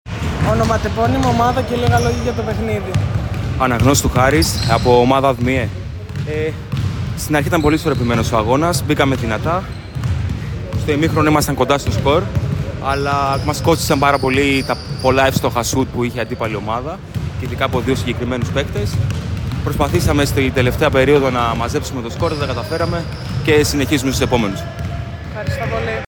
GAMES INTERVIEWS: